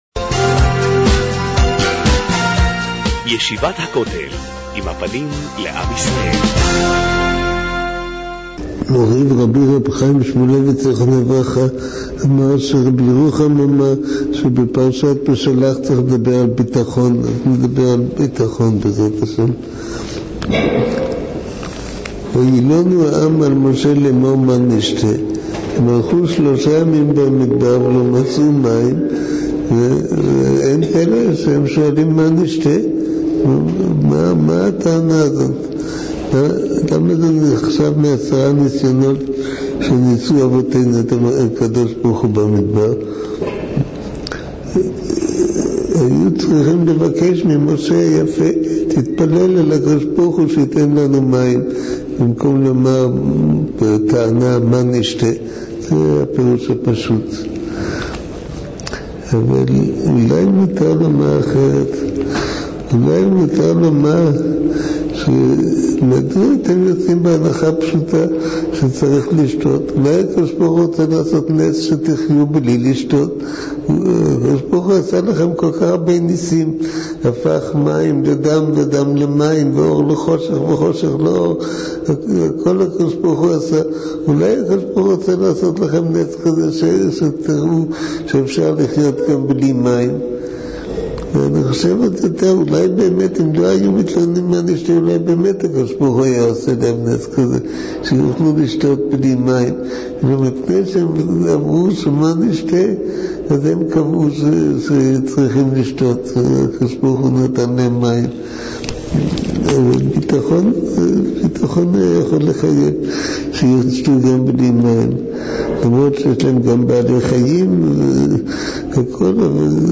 מעביר השיעור: מו"ר הרב אביגדר נבנצל